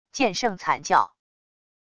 剑圣惨叫wav音频